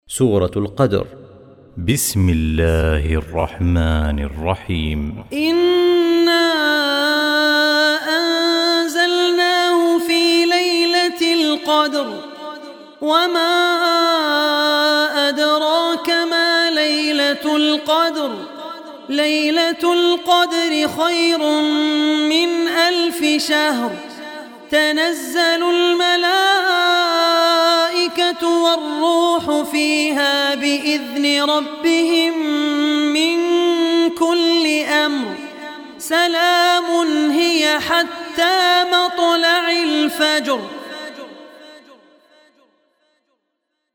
Surah Al-Qadr Recitation
97-surah-qadar.mp3